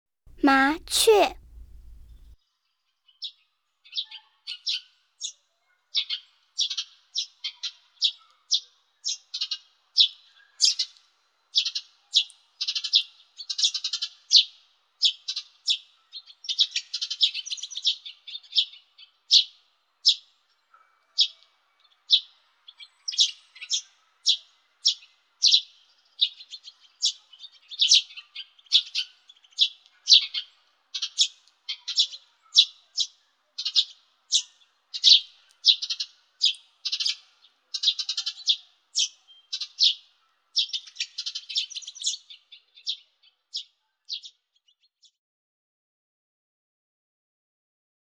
来自天际、山林、乡野、水涧、海岸的鸣唱是如此丰富美好。